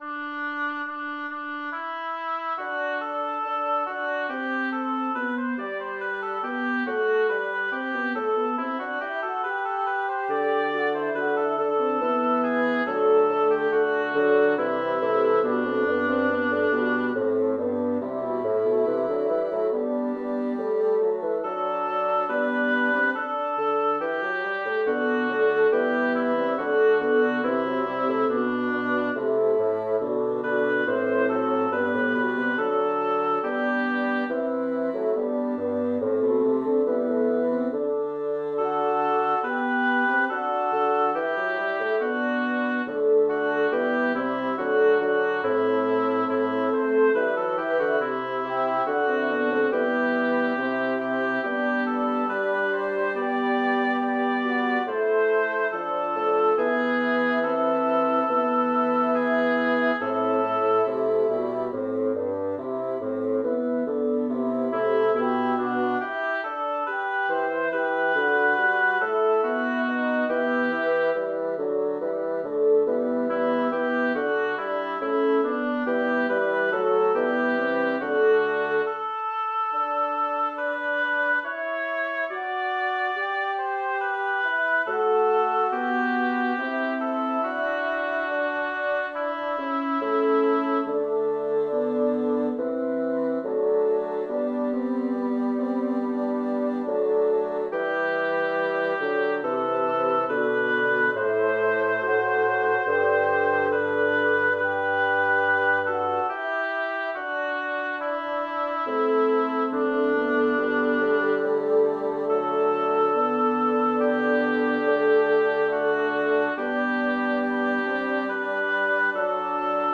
Title: D'un sì bel foco Composer: Felice Anerio Lyricist: Luigi Tansillo Number of voices: 4vv Voicing: SAAT Genre: Secular, Madrigal
Language: Italian Instruments: A cappella